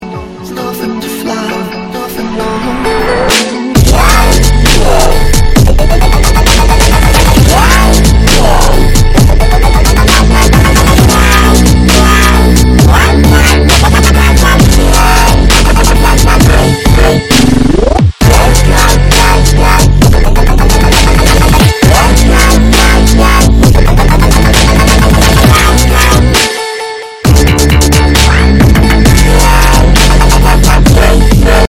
• Качество: 128, Stereo
Электро